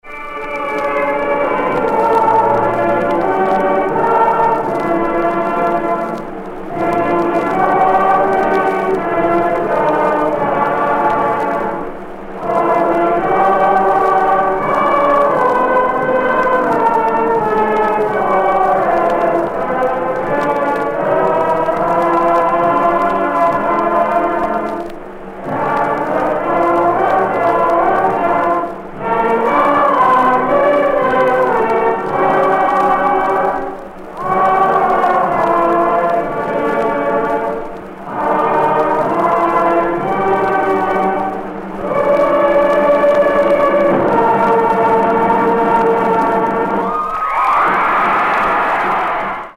AFTER THE FOOTBALL GAME